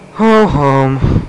Ho Hum Sound Effect
ho-hum.mp3